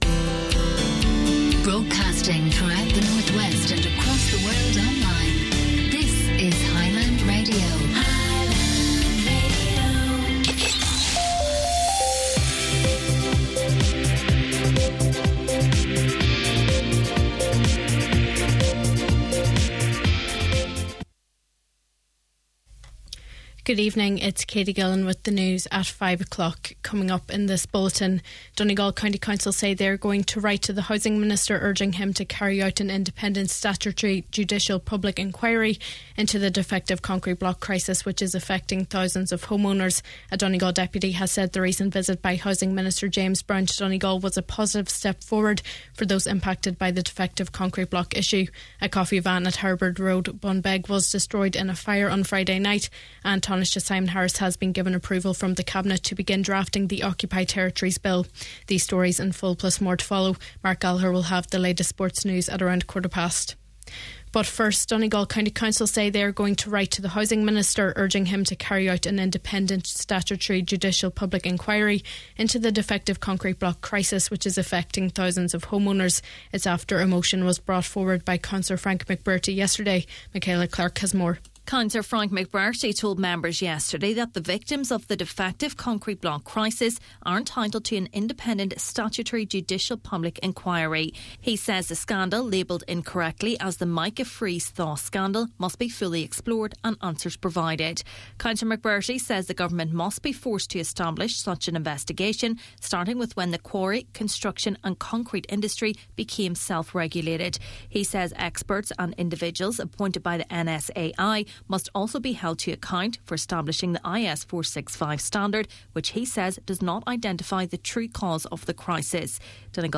Main Evening News, Sport and Obituaries – Tuesday May 27th
news-may-27th.mp3